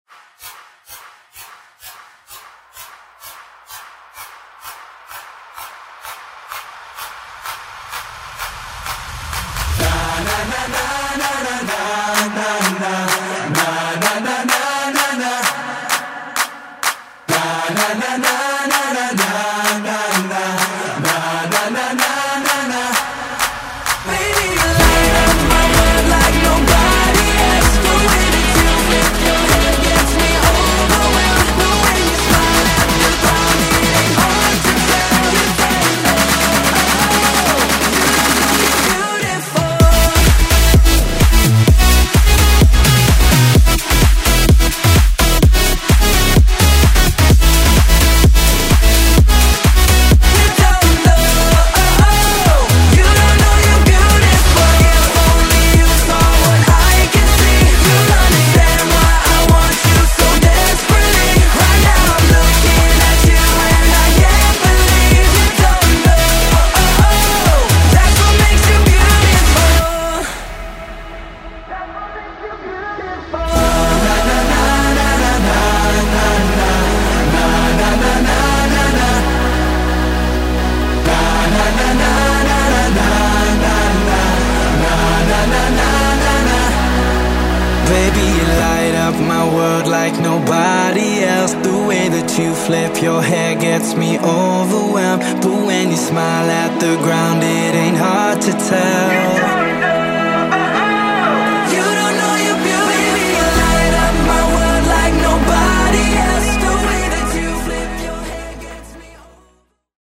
Genre: BOOTLEG
Clean BPM: 124 Time